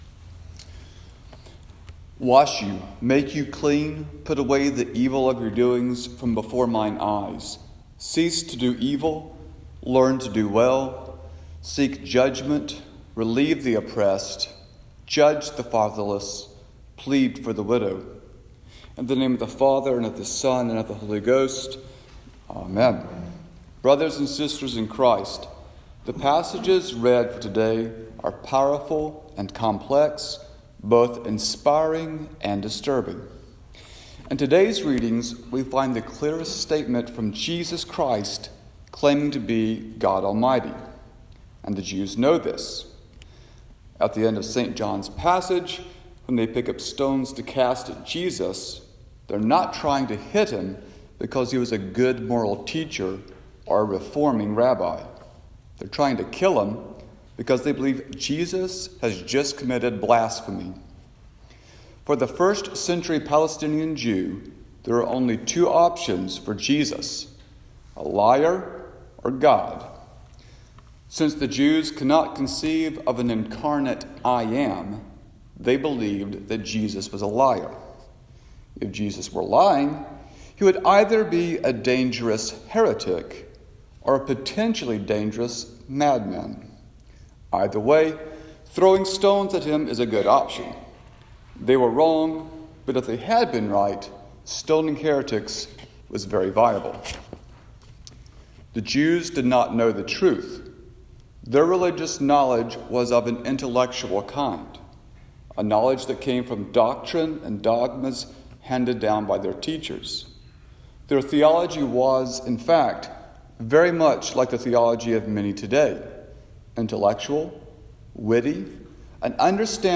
Sermon-For-Passion-Sunday.m4a